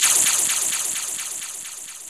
SI2 ECHO  0J.wav